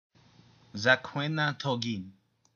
The Drakona alphabet or Drakona script (Eda.: ɒɿɔɞɷʅ̆ɔ ɾɷɜȷɤ; Dzakoena togin; Edazoran pronunciation:
Listeni/ˈzɑˌkw.nɑ ˈtˌɡn/, Drak.: ɒʊ̆ɞɷɤɔ ɾ̆ɜȷɤ; Drakona thogin; Drakona pronunciation: